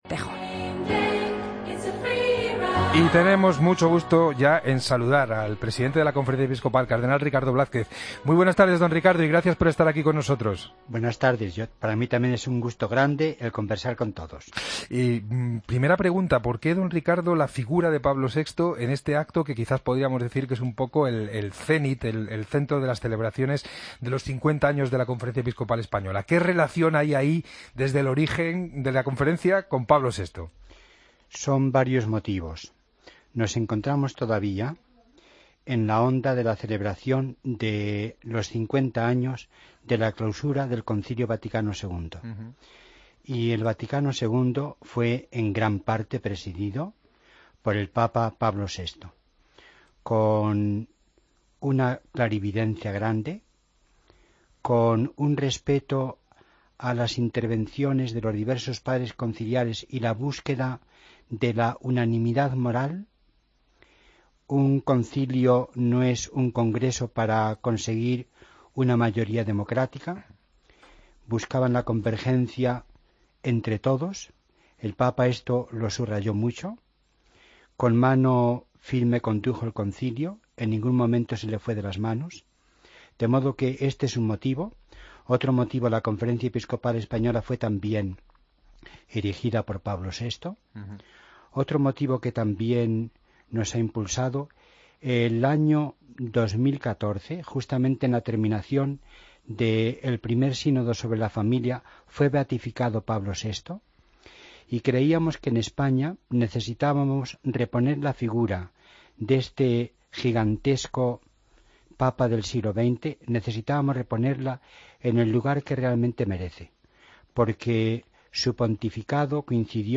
Escucha la entrevista al cardenal Monseñor Ricardo Blázquez en 'El Espejo'